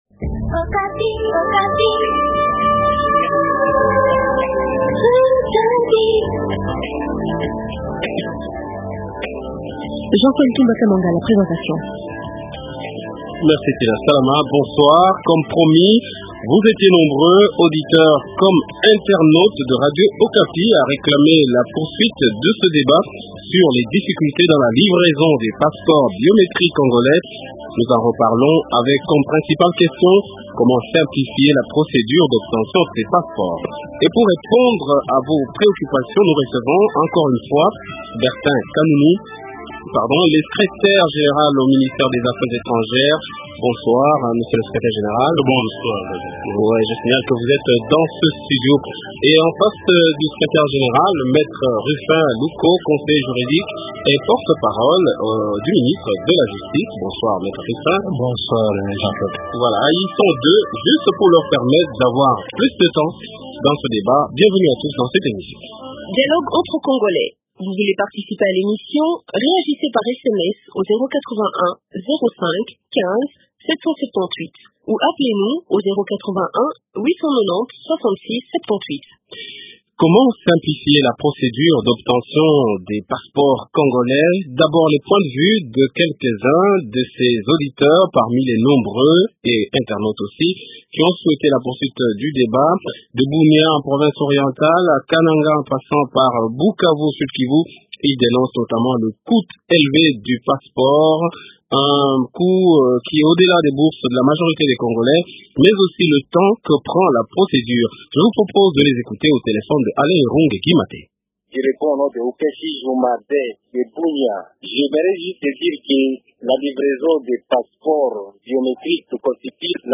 A la demande des nombreux auditeurs et internautes de radio okapi, Dialogue entre congolais poursuit ce mercredi 19 août 2009 le débat sur les difficultés dans la livraison des passeports biométriques congolais.